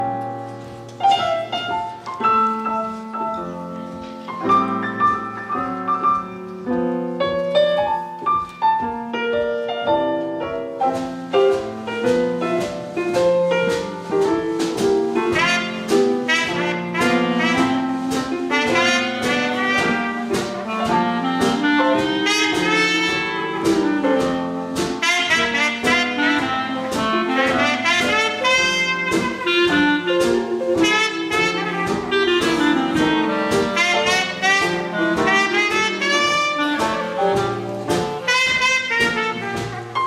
trompette
clarinette, saxophone ténor
piano
batterie